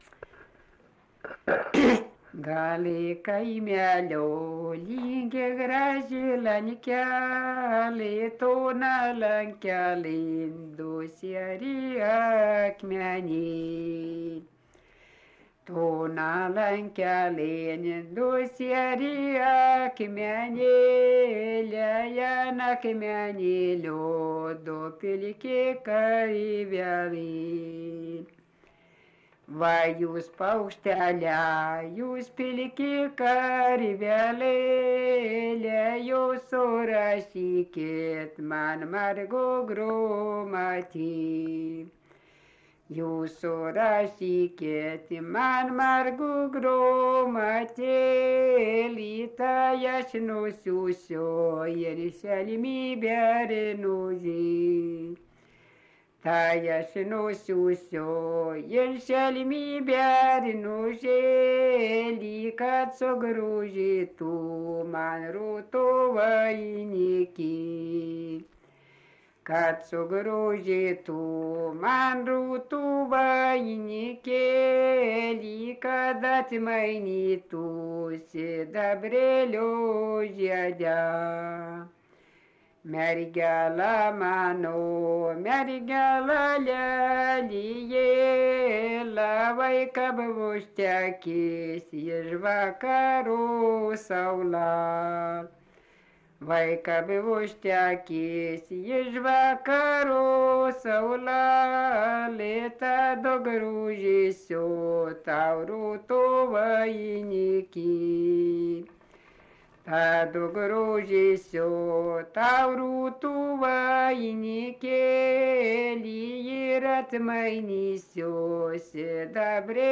Dalykas, tema daina
Erdvinė aprėptis Mardasavas
Atlikimo pubūdis vokalinis